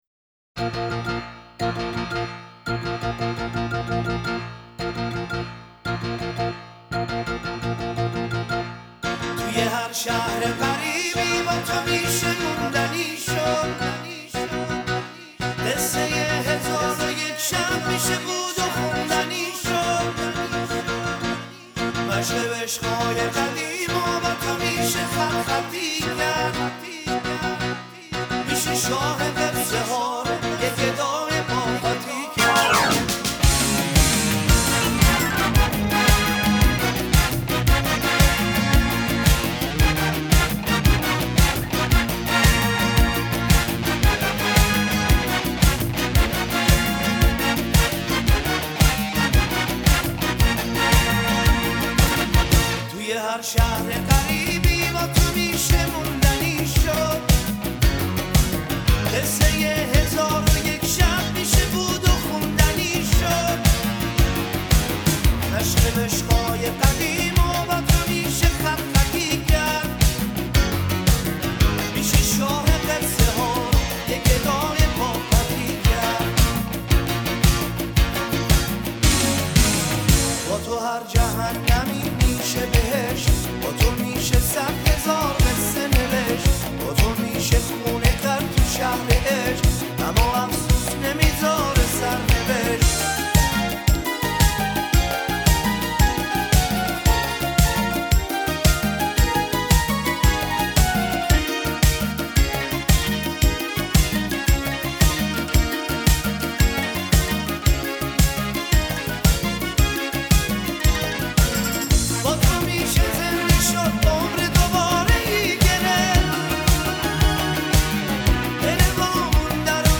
اهنگ شاد ایرانی
اهنگ شاد قدیمی